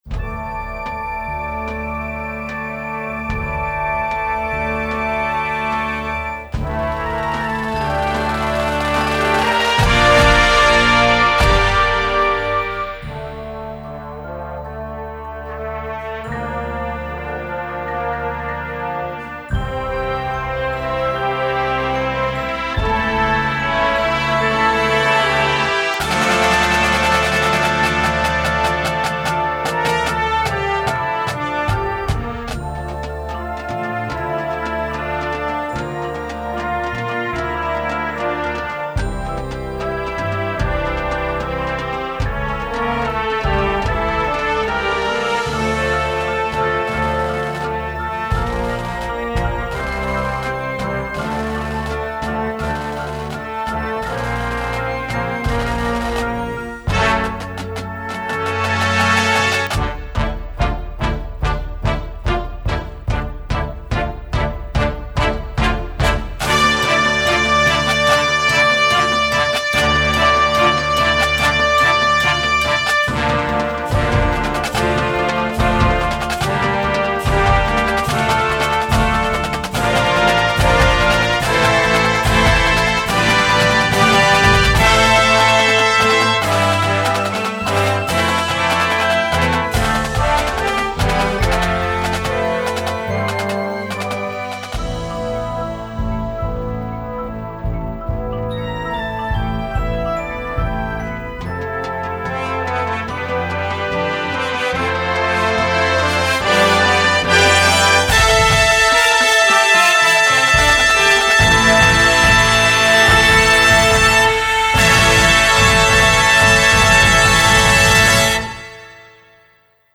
Gattung: Filmmusik
B-C Besetzung: Blasorchester Tonprobe